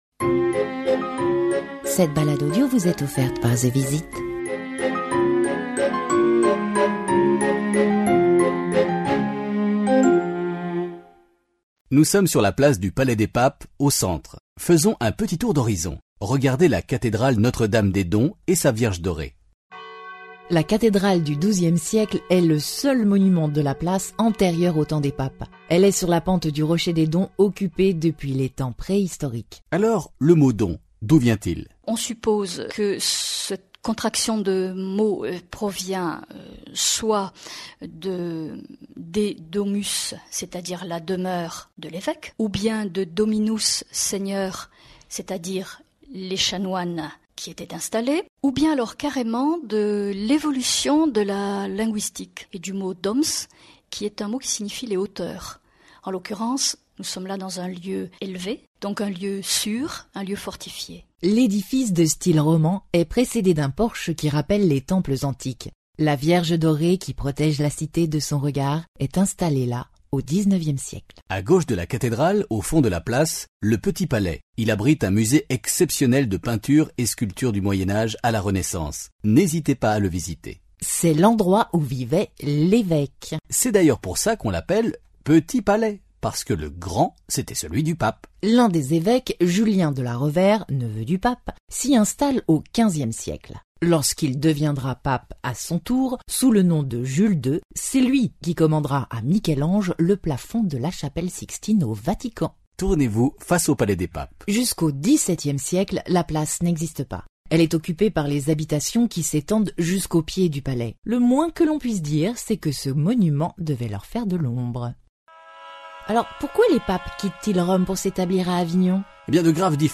comme d'hab, le babil historique est insupportable. cet accent si parisien, est une souffrance par son omniprésence et son outrecuidance normative.
zevisit est une sorte d'audio-guide, je vous avoue que suis allergique aux audio-guides en général